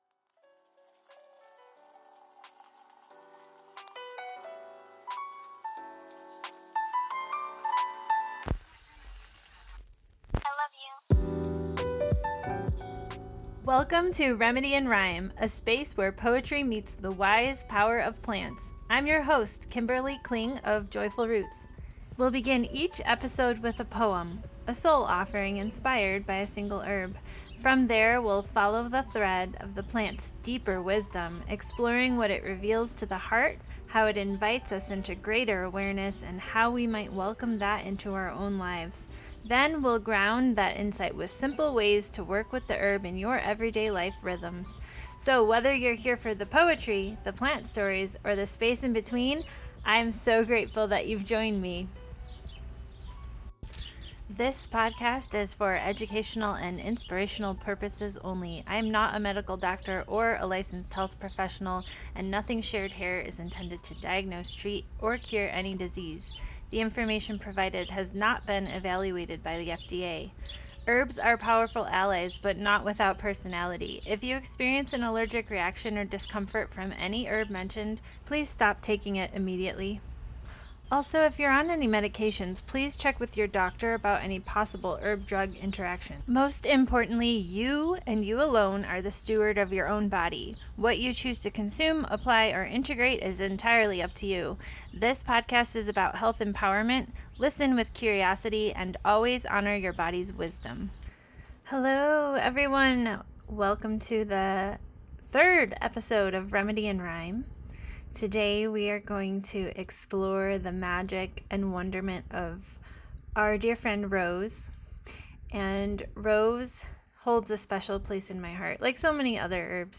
🌹 Each episode will begin with a poem and flow into a deeper dive into the featured herb—its stories, energetics, remedies, and reflections.